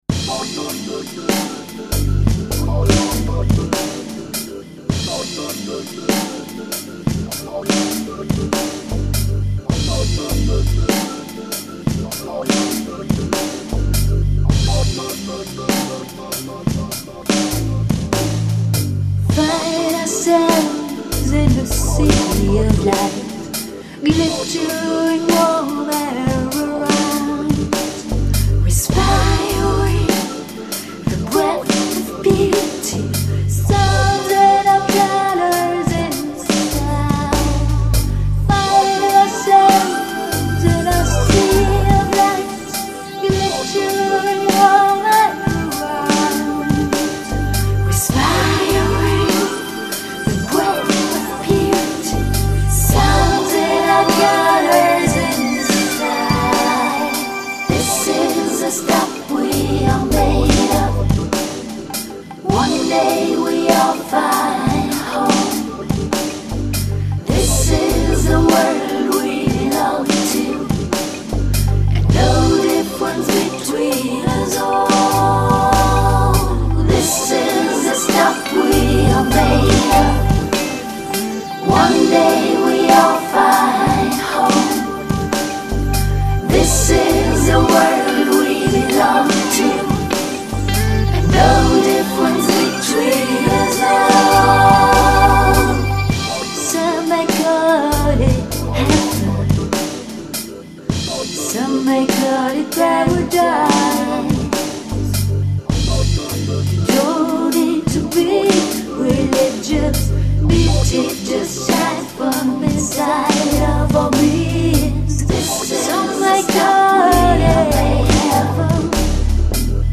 vocals, sax